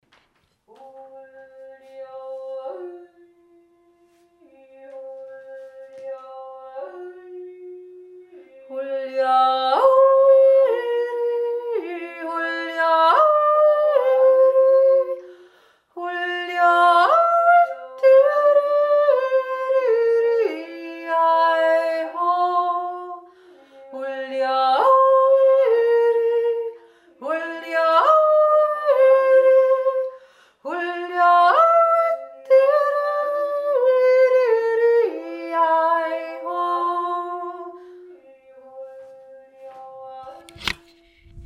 unser Jodelmantra
3. Stimme